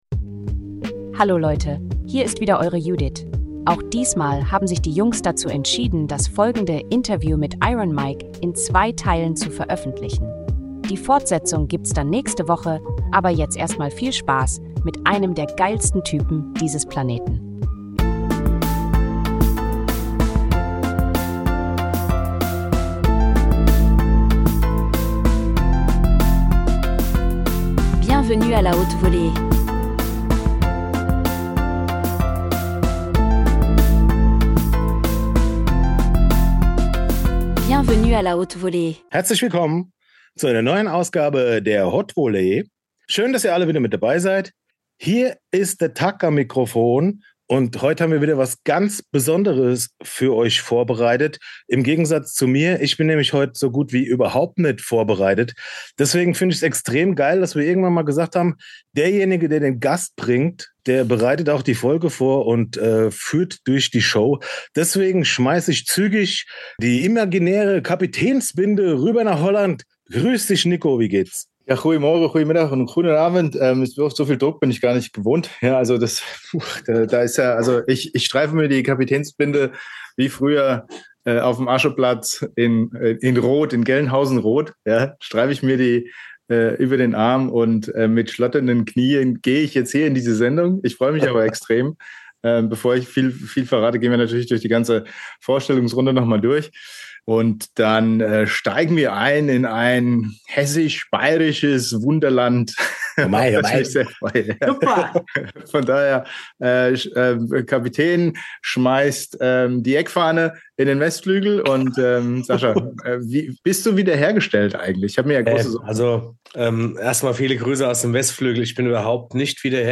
Ein Interview zwischen Nostalgie, Medienwelt und dem Beweis, dass aus (einigen von) uns doch noch was geworden ist.